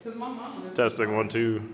F# 4/4 126
Gospel